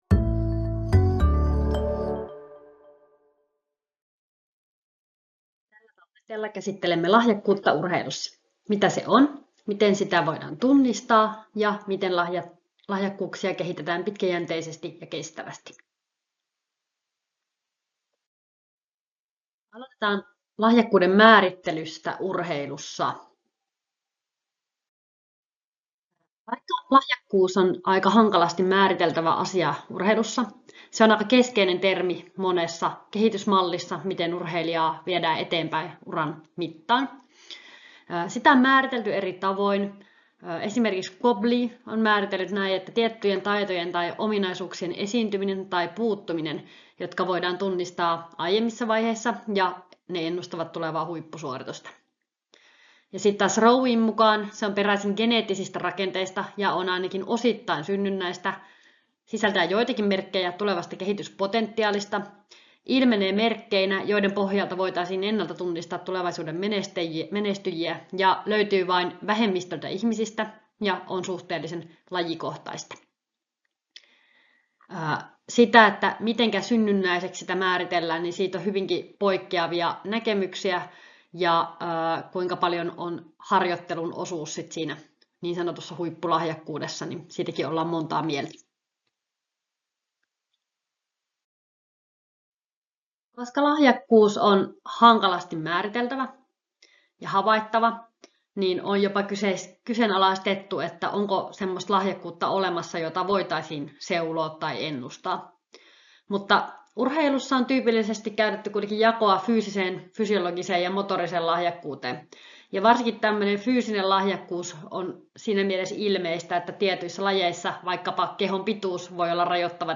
luento lahjakkuudesta urheilussa